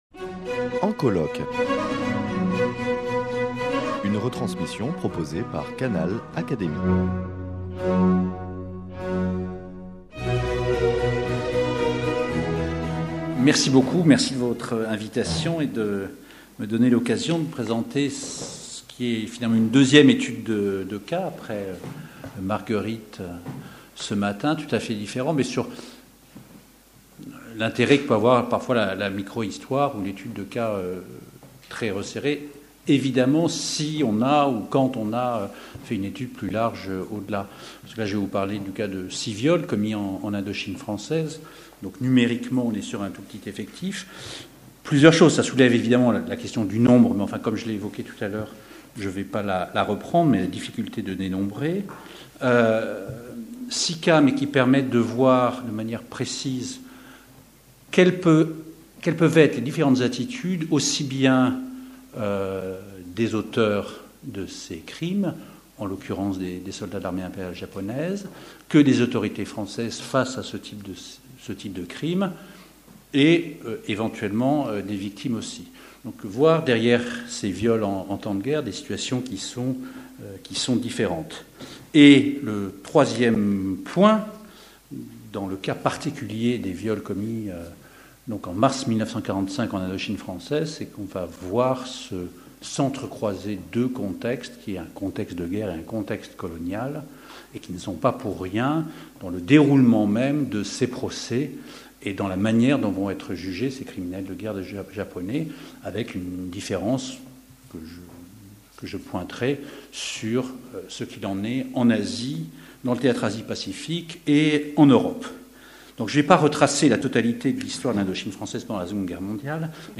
prononcée le 13 janvier 2016 lors des journées d’étude « La guerre et les femmes »